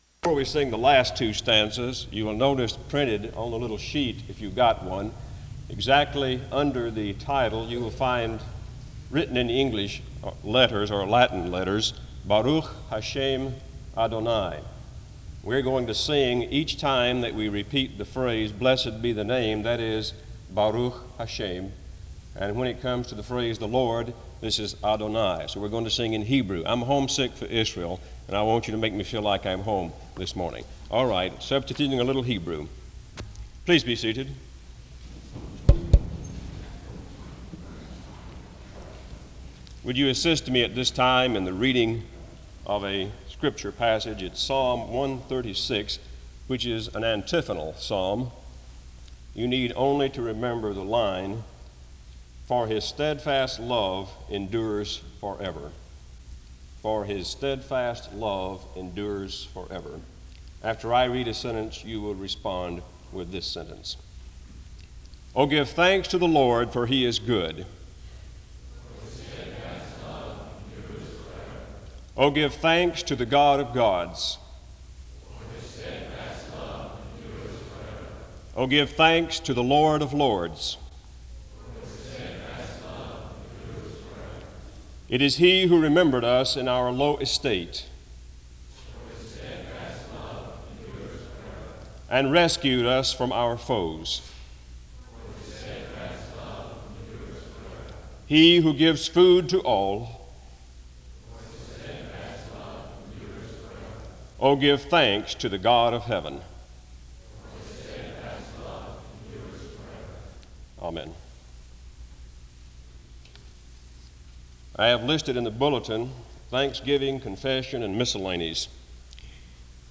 The service begins with an announcement about a short revision to an upcoming song the chapel would sing from 0:00-0:34. Psalm 136 is read from 0:40-1:53.
A choir sings a Hebrew anthem from 5:31-6:14.
SEBTS Chapel and Special Event Recordings